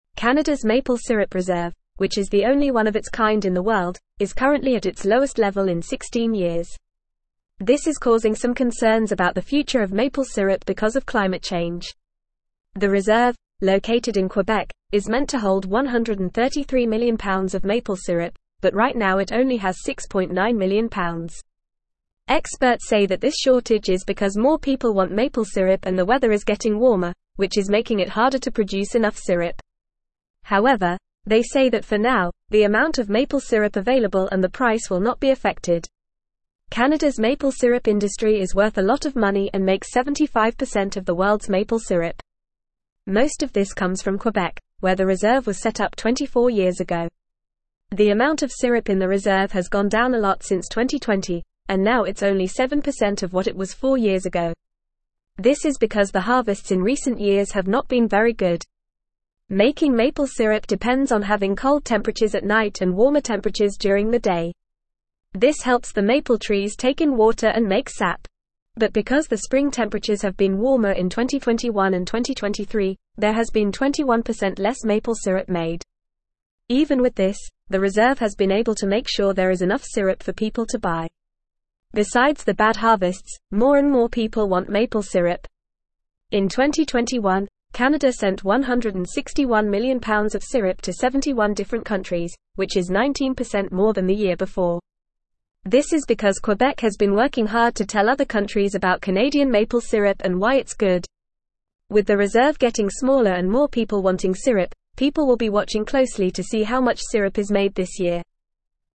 Fast
English-Newsroom-Upper-Intermediate-FAST-Reading-Canadas-Maple-Syrup-Reserve-Reaches-16-Year-Low.mp3